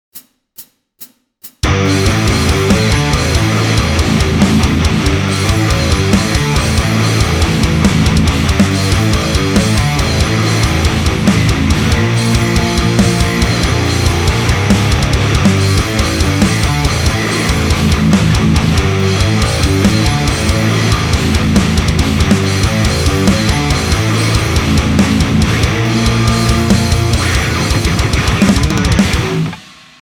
������ ����������� (revalver, superrior drummer)